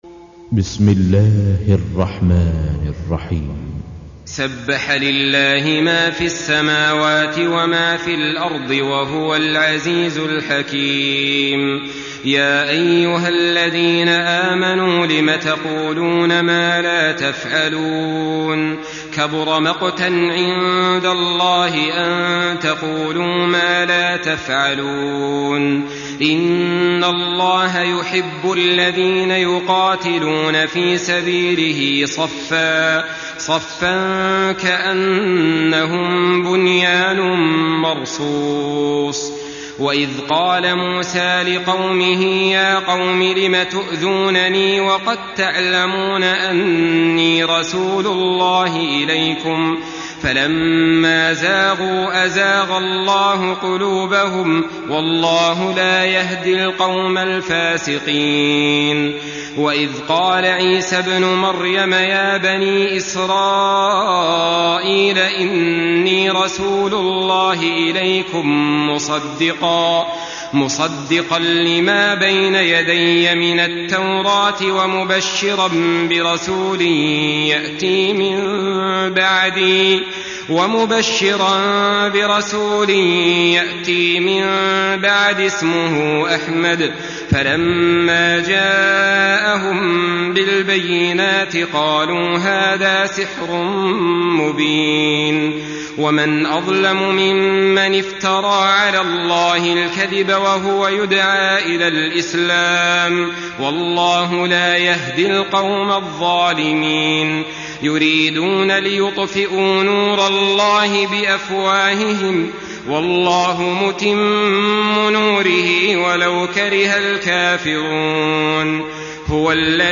Surah As-Saf MP3 by Saleh Al-Talib in Hafs An Asim narration.
Murattal Hafs An Asim